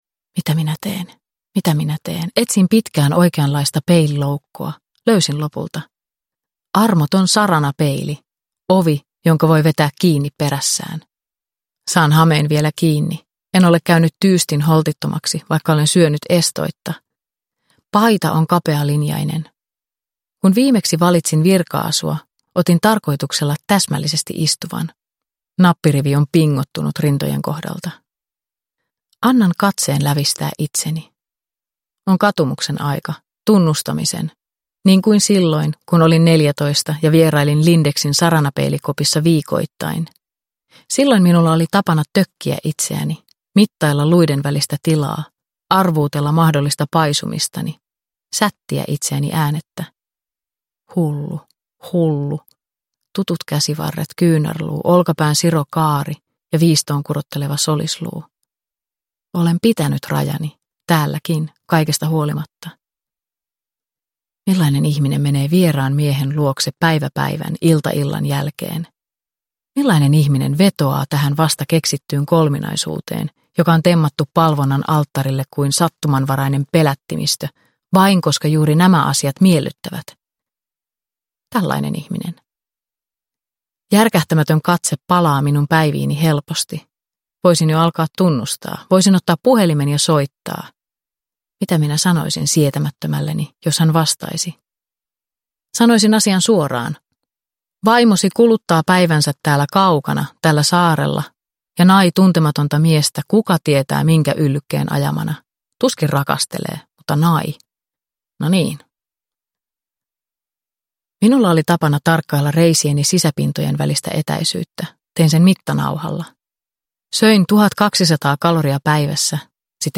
Vieras – Ljudbok – Laddas ner